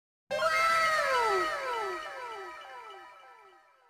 2.anime-wow
2-anime-wow.mp3